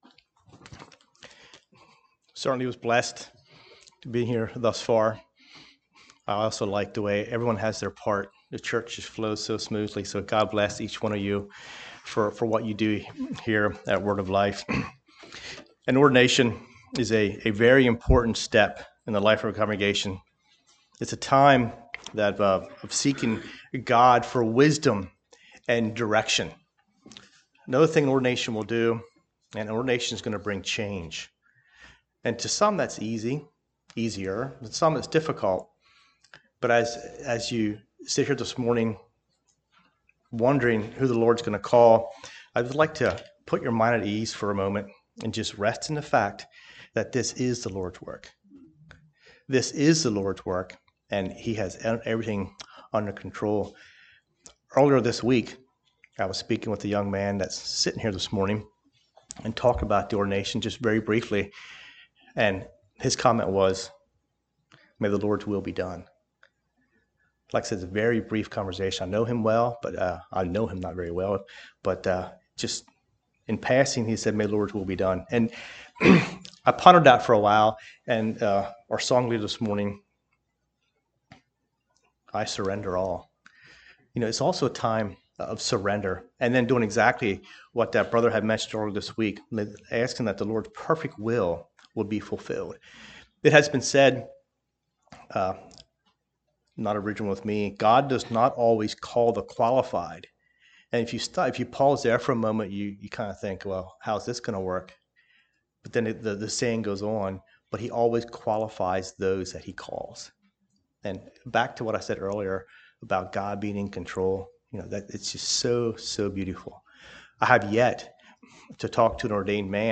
Listen to sermon recordings from Word of Life Mennonite Fellowship.